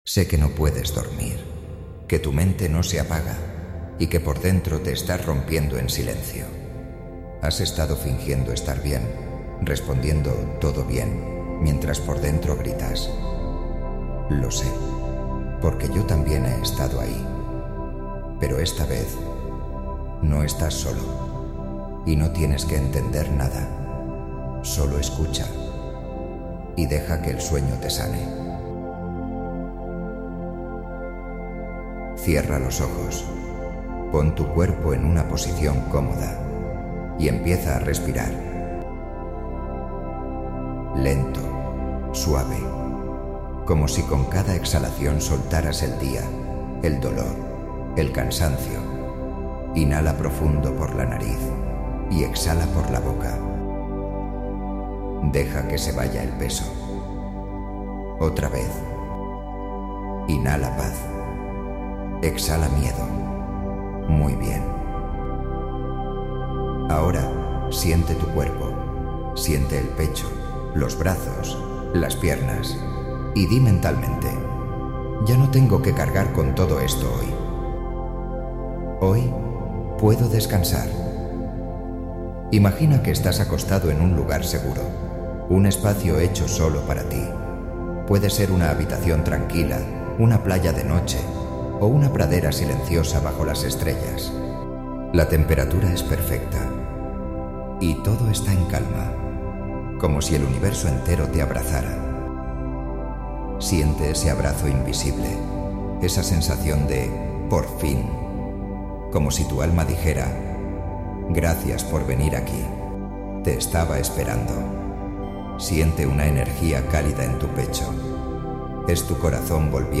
Esta meditación guiada está diseñada sound effects free download
Esta meditación guiada está diseñada para ayudarte a soltar el estrés del día, calmar tu mente y entrar en un sueño profundo y reparador 😴✨. Pon tus audífonos, relájate y permite que mi voz te acompañe hacia un descanso lleno de paz y energía renovada.